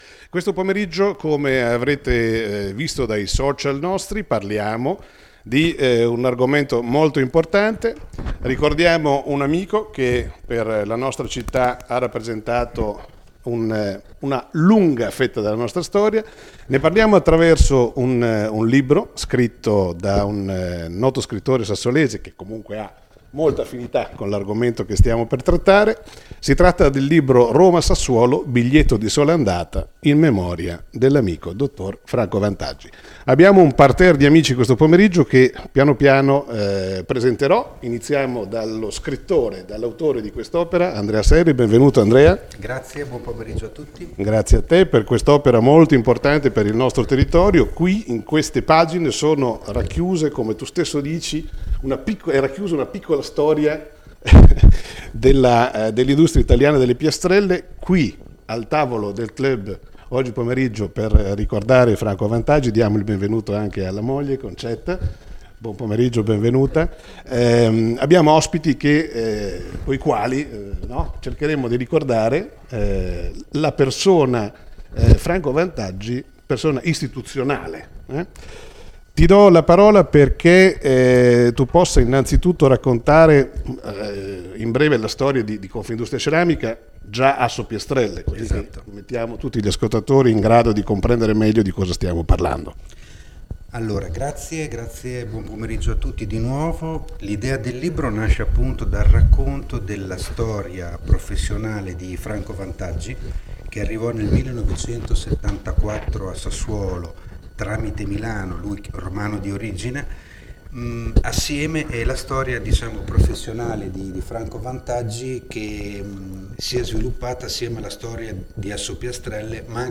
Diretta Linea Radio 18/01 dal Clhub di viale XX Settembre a Sassuolo